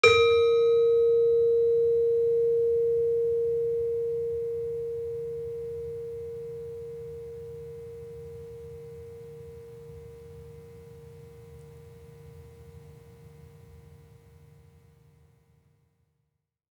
HSS-Gamelan-1
Gender-3-A#3-f.wav